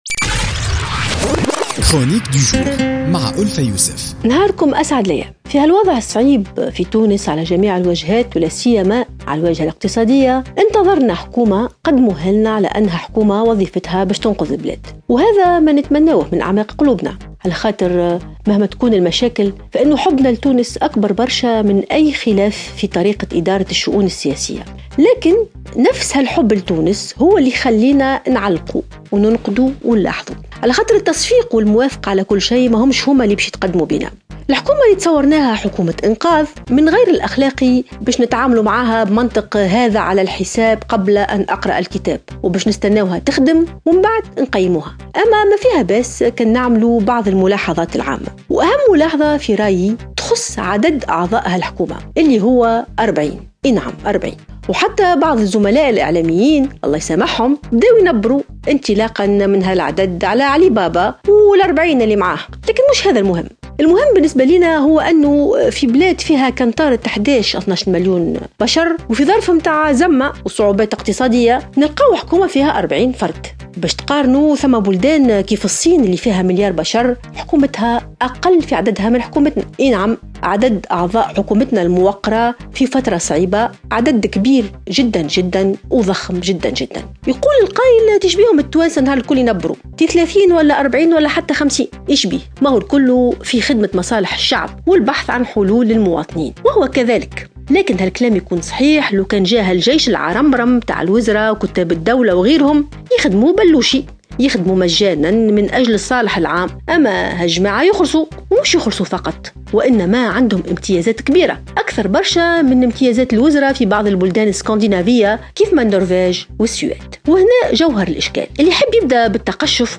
تطرقت الكاتبة ألفة يوسف في افتتاحية اليوم الجمعة 26 أوت 2016 إلى تركيبة حكومة يوسف الشاهد التي ناهزت 40 وزيرا مستنكرة أن تكون الحكومة مكونة من هذا العدد الكبير والضخم من الوزراء في بلد لايتجاوز عدد سكانه ال12 مليون ساكنا ويواجه وضعا اقتصاديا صعبا للغاية .